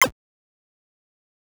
Chiptune Sample Pack
8bit_FX_C_01_03.wav